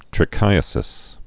(trĭ-kīə-sĭs)